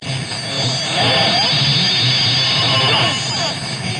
噪声和尖叫声 " 噪声2
描述：采用Sond Forge 10 + VST FX制成
Tag: 机械 电子 噪音 外国人